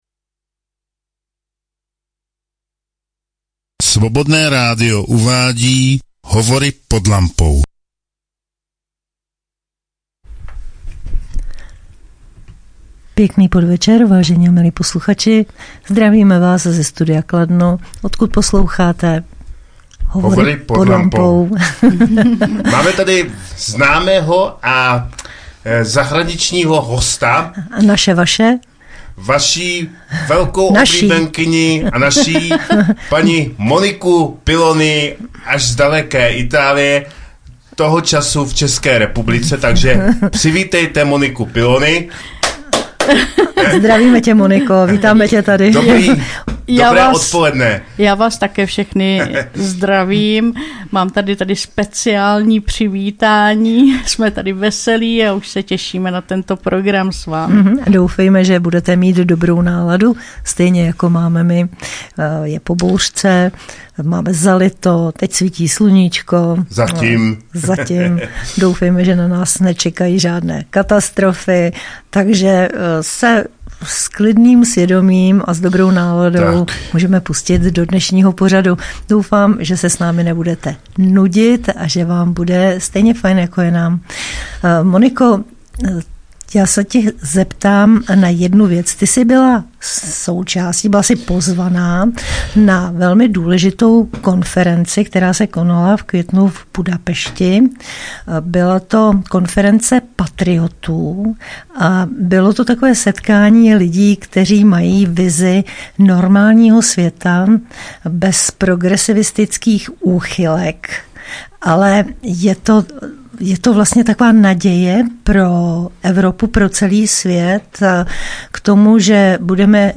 Studio Kladno - Hovory pod lampou